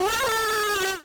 pbs - metro II [ Vox ].wav